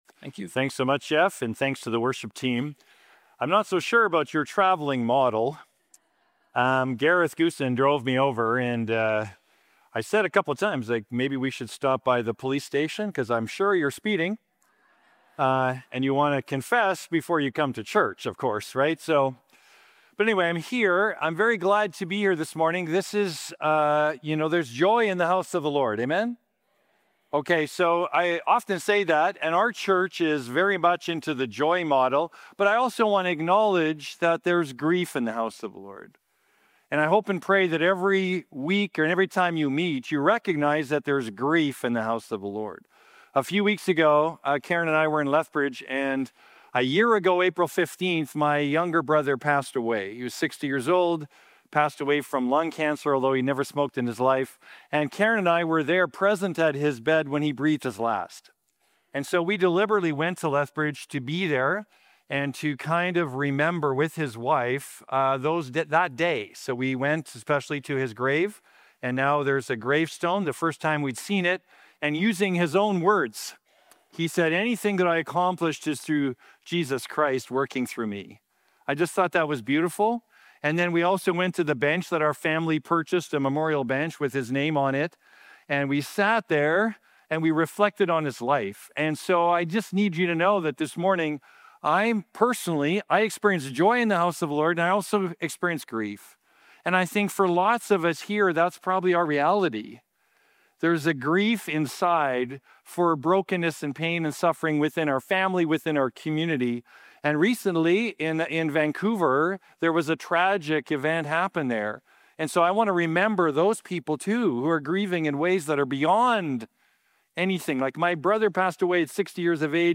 The Church & God's Kingdom | Guest Speaker